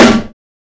Kanye Type snare.wav